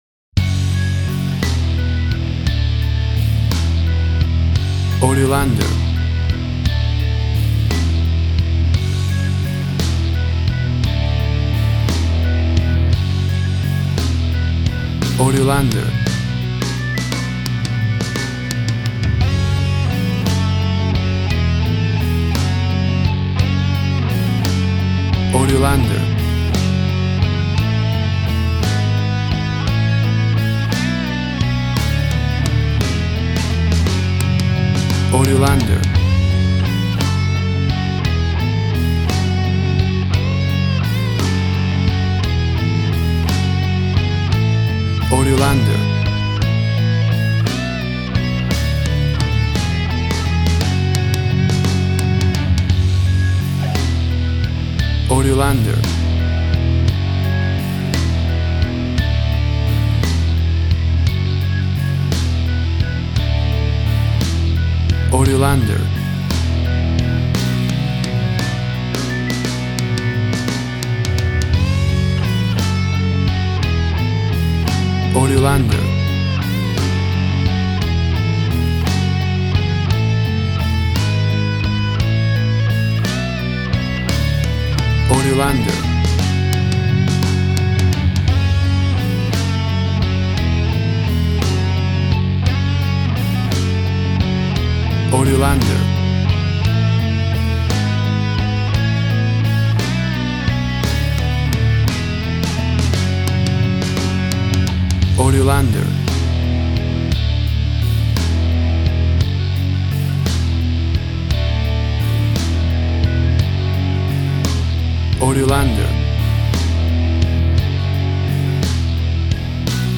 A big and powerful rocking version
Full of happy joyful festive sounds and holiday feeling!
WAV Sample Rate 16-Bit Stereo, 44.1 kHz
Tempo (BPM) 175